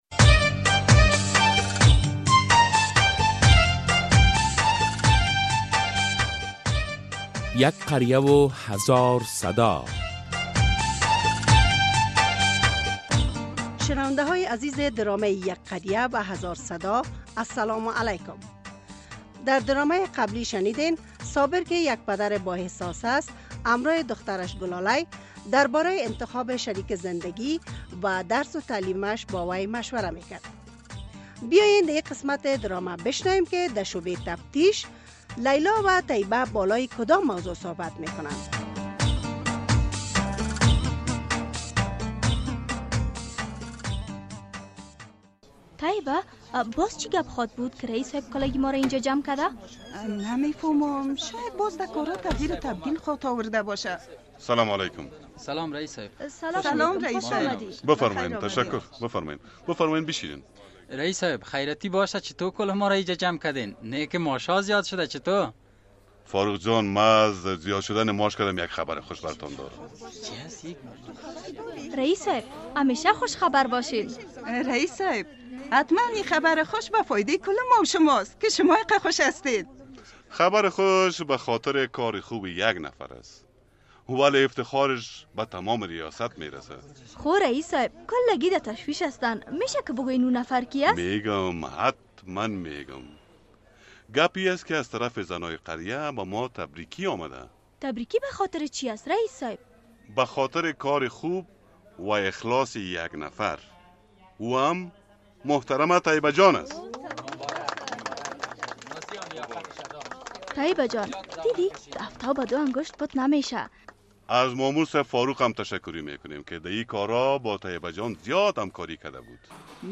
درامه یک قریه هزار صدا قسمت ۲۰۱